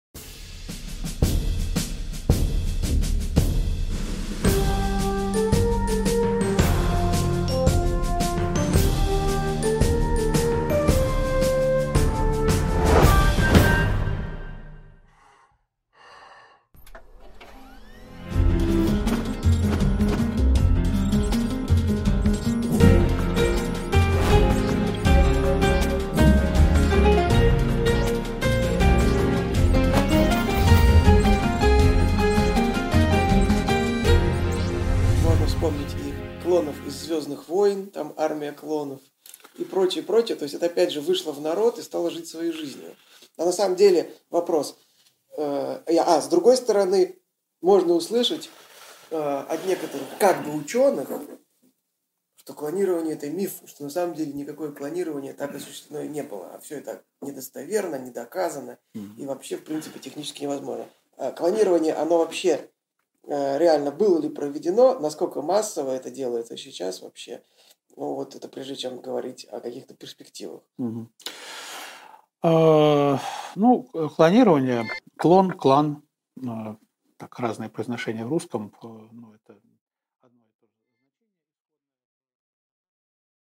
Аудиокнига Правда и мифы о клонировании | Библиотека аудиокниг